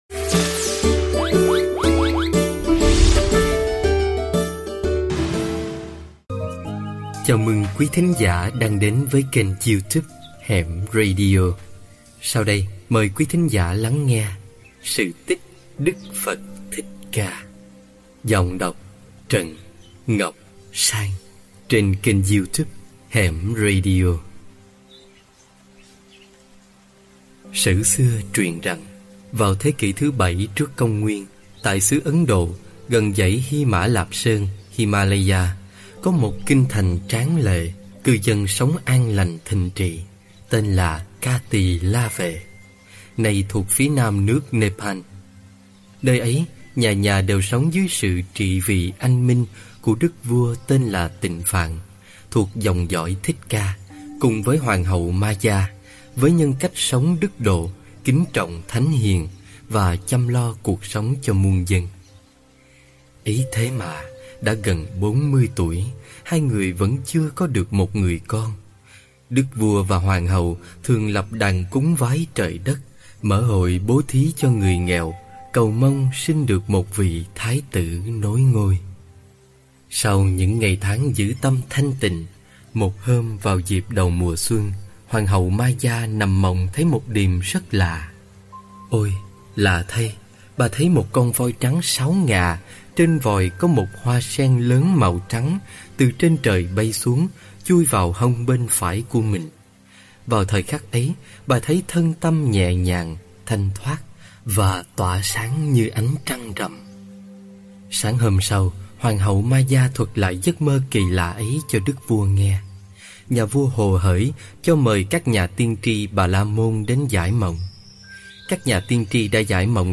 Quý khách có thể mua sách gốc để ủng hộ bản quyền tác giả tại đây: MUA NGAY Bạn đang nghe sách nói Sự Tích Đức Phật Thích Ca | Saigon Books Audio | Bản Full.